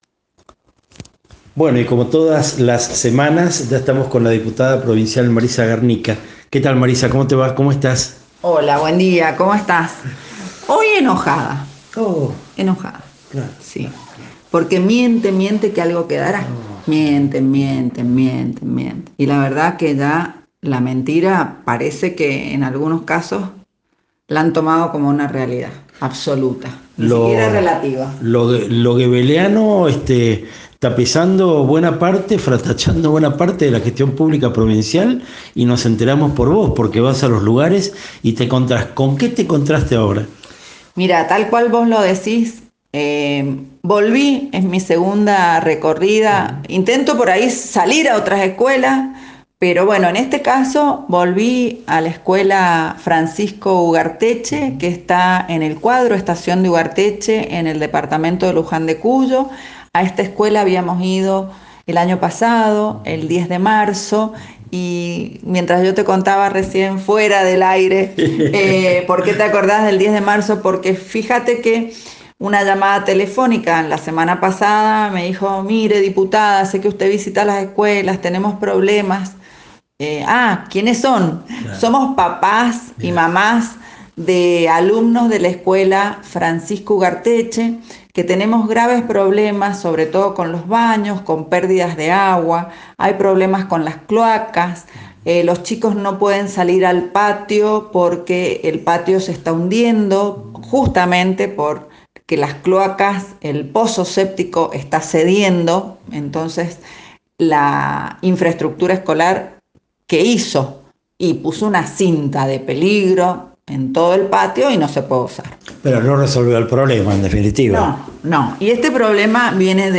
Entrevista: Marisa Garnica, Diputada Provincial, 21 de marzo de 2023